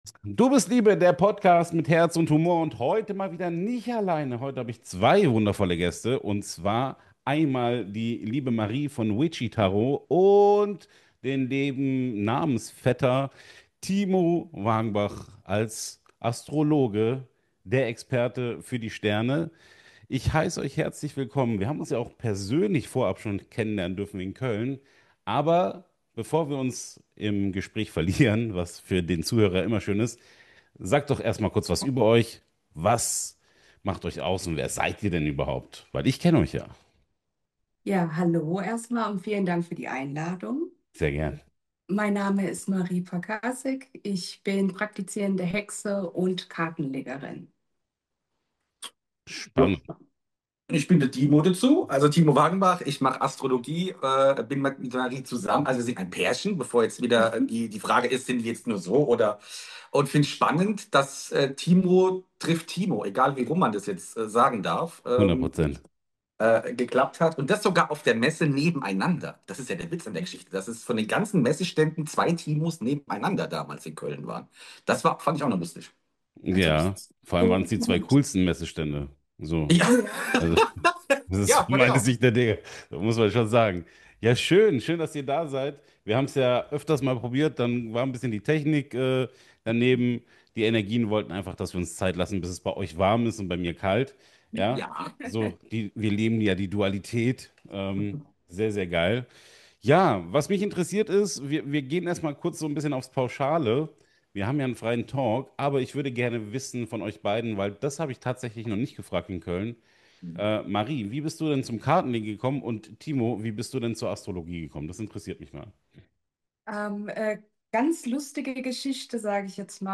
In dieser Folge habe ich einen wundervollen Talk